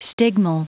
Prononciation du mot stigmal en anglais (fichier audio)
Prononciation du mot : stigmal